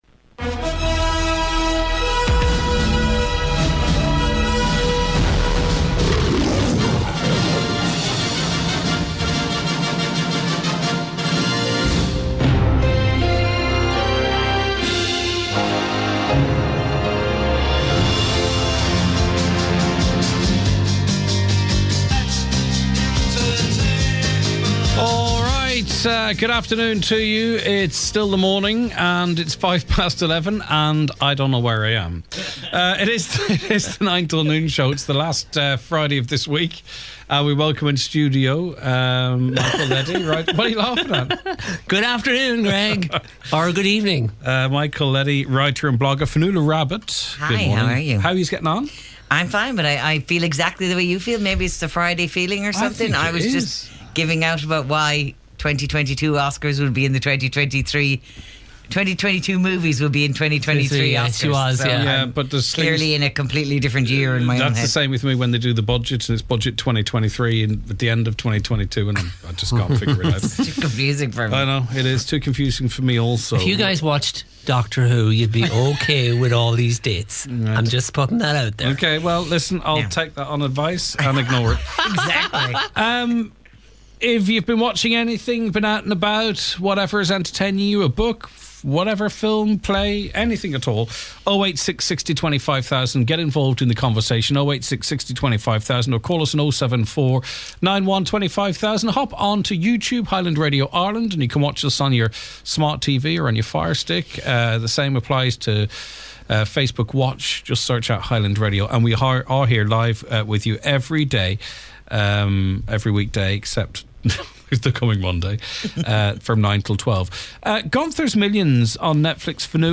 Weekday’s 9am to 12noon Magazine type mid morning chat show providing a forum for listeners to express their concerns on the issues of the day.